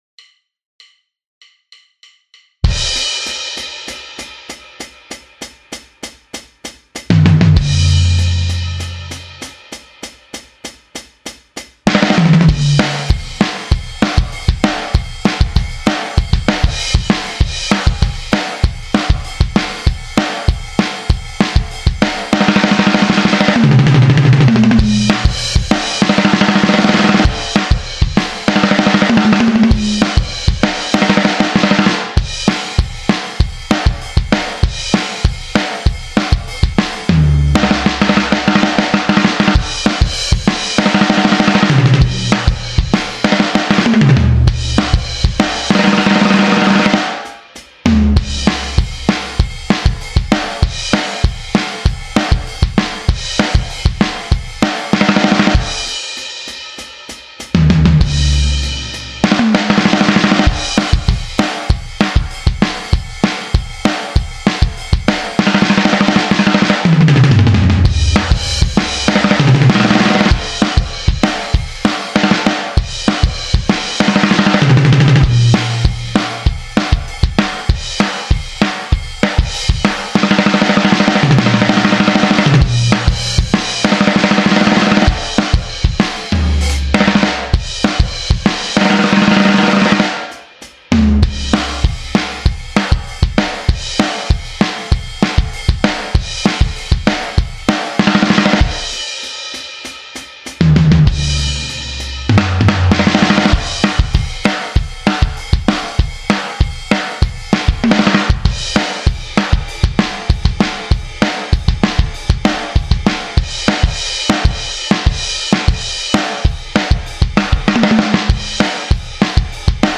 Rock Kit